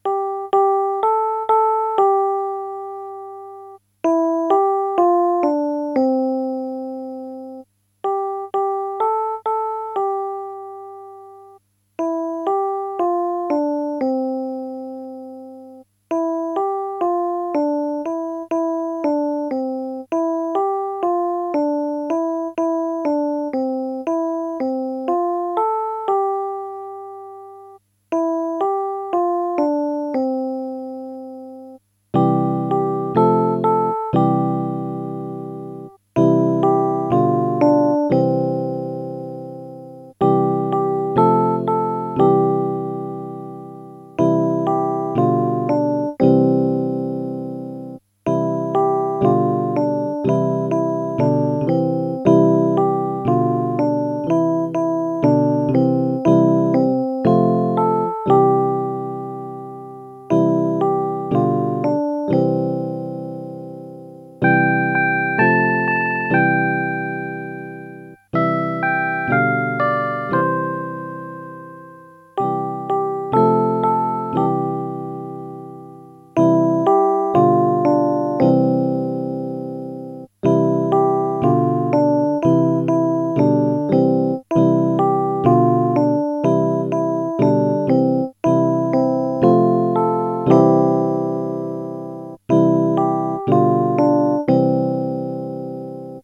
-         prvá sloha klavír pravá ruka
-         druhá sloha jednoduchý doprovod (klavír ľavá ruka)
-         bez spevu